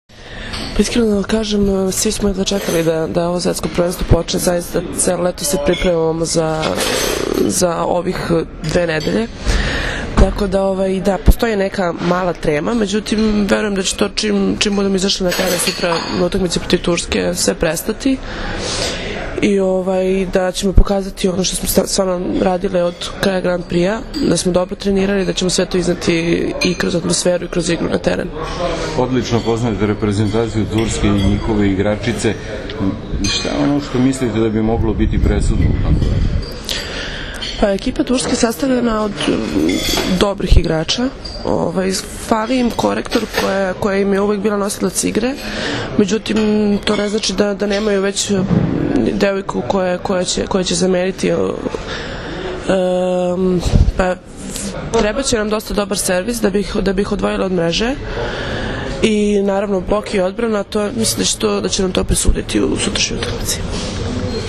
IZJAVA MILENE RAŠIĆ